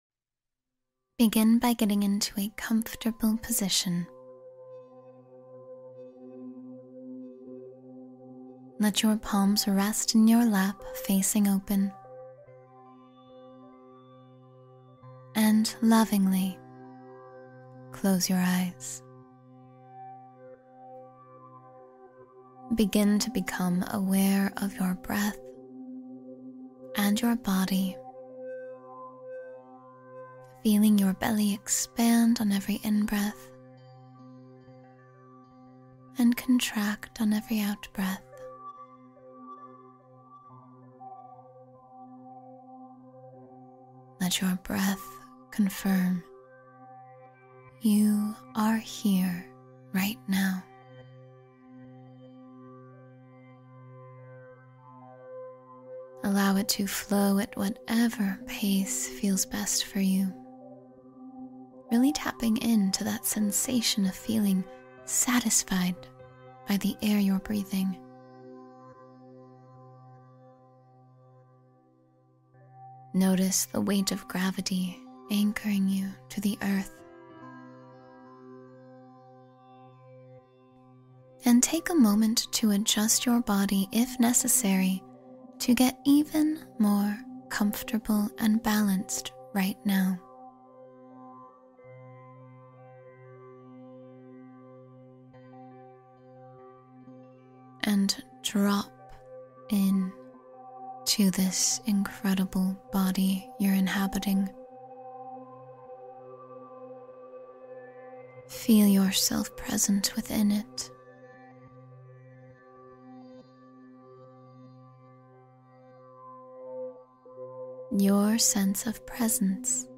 This choice exists to support the show while protecting the moment you press play, that moment when rain sounds begin and your body instantly recognizes safety.